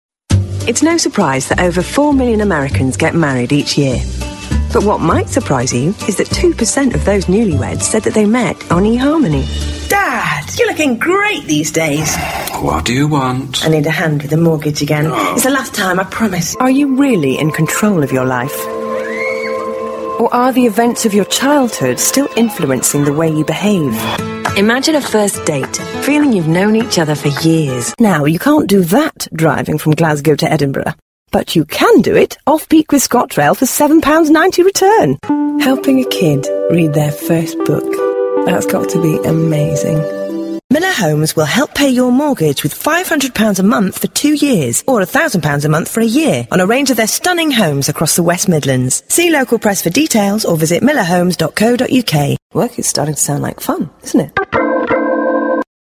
خدمة نقل الصوت باللغة الإنجليزية
صوت إنجليزي
نعطي صوتًا لمشروعاتك الصوتية والدبلجة مع أشهر ممثلي الصوت الأصليين في العالم بلهجات أمريكية وبريطانية.